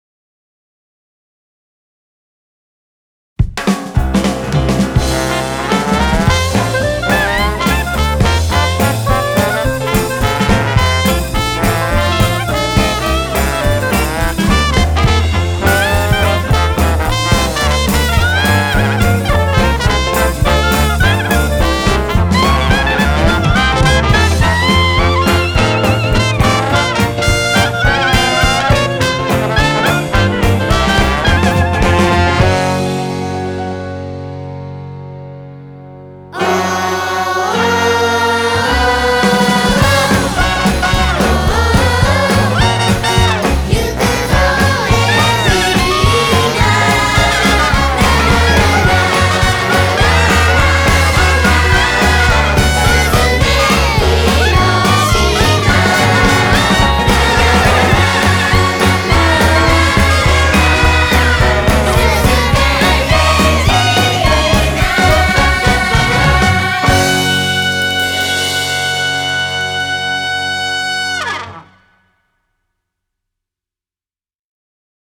BIG BAND / JAZZ
レコーディングではアドリブを活かし、ライブ感と高揚感をそのまま音に。
後半には大人と子どもあわせて14人の合唱が加わり、楽しさの中に思わず胸が熱くなる瞬間を重ねました。
Trombone
Trumpet
Clarinet
Drums